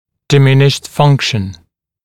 [dɪ’mɪnɪʃt ‘fʌŋkʃn] [ди’миништ ‘фанкшн] пониженная функция